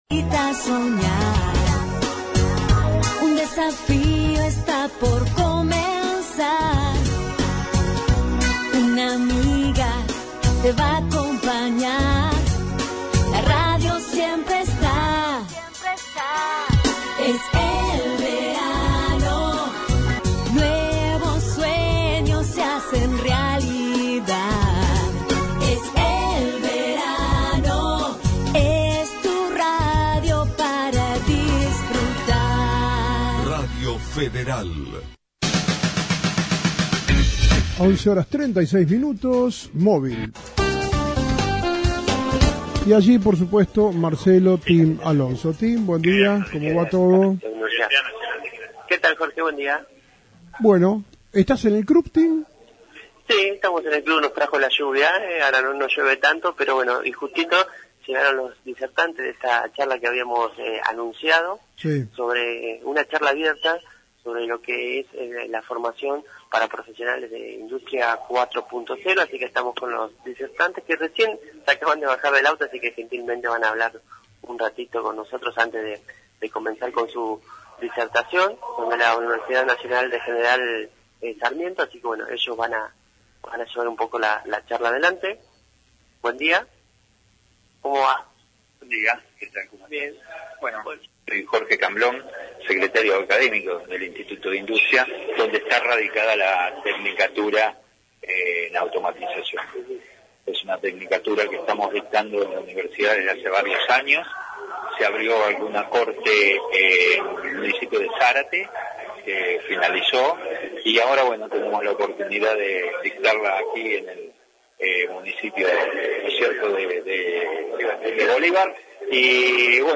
Disertantes de la Charla